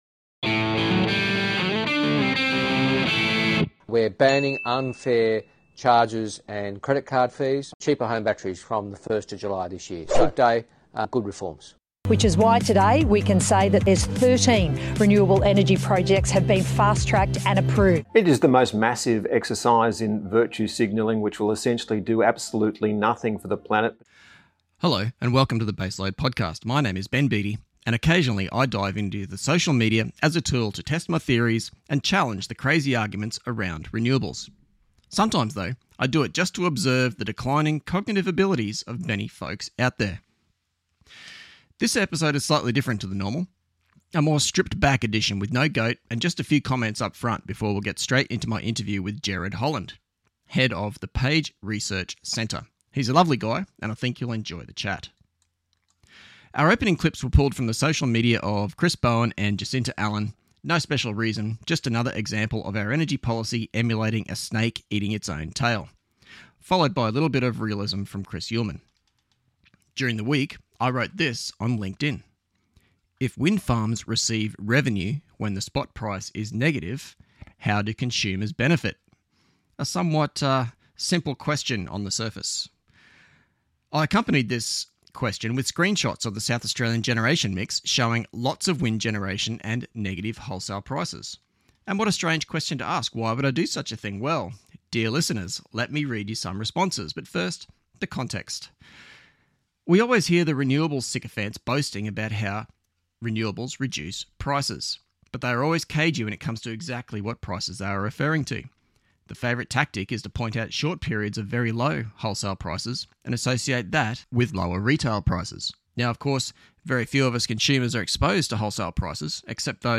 Shorter episode this week, no goat, just a quick chat and an interview.